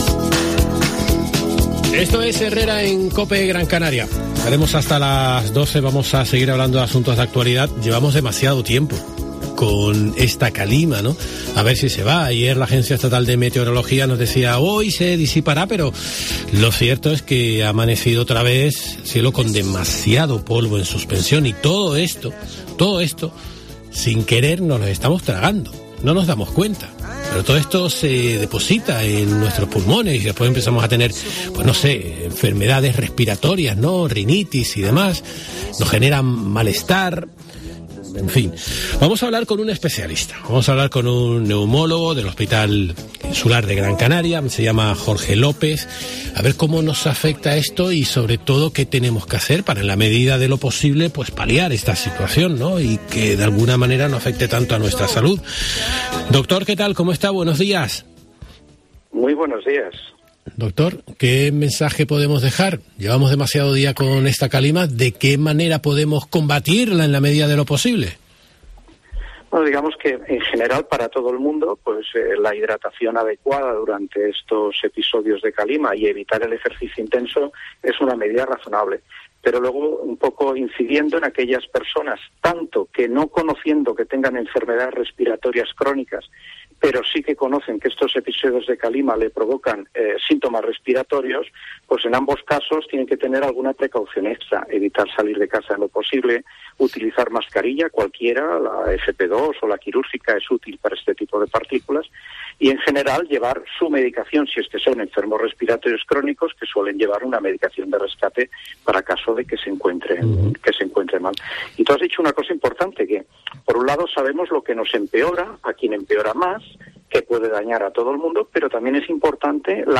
En Herrera en COPE Canarias hemos hablado con un especialista